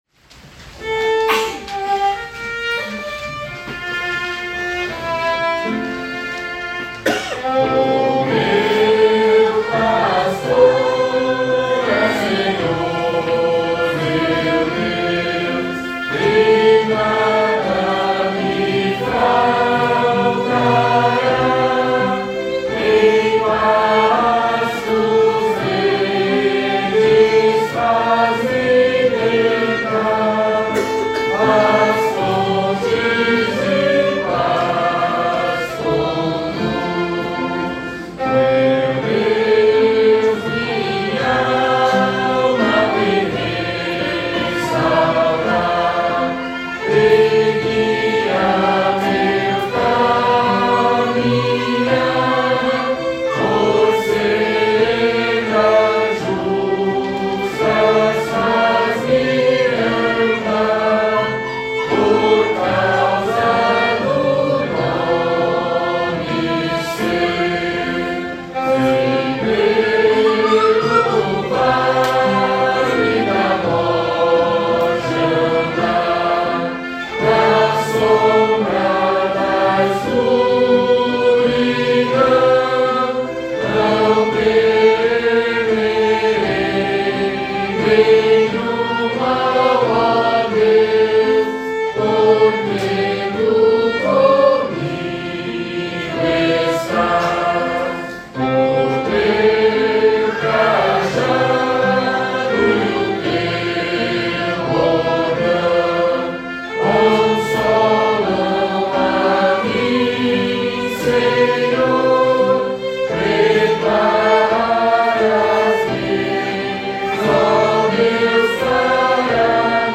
Métrica: 9 7. 9 7
salmo_23B_cantado.mp3